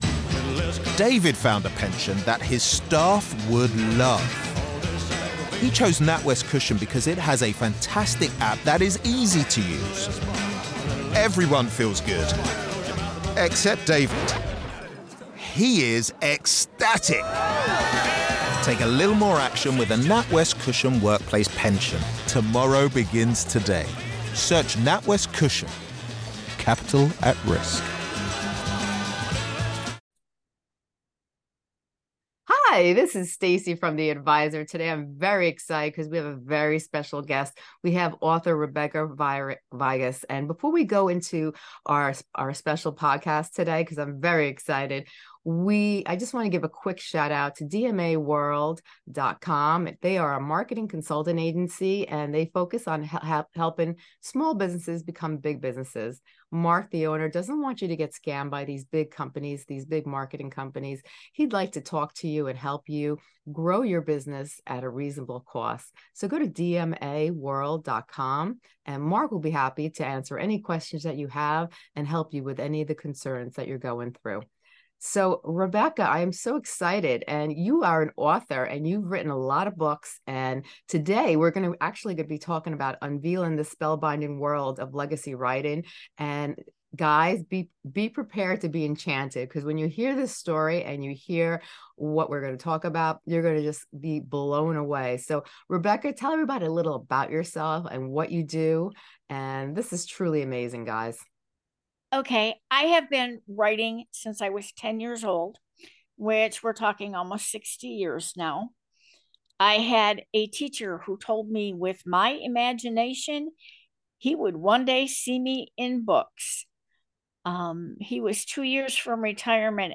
The conversation underscored the value of documenting family history beyond material wealth, emphasizing the importance of storytelling for future generations.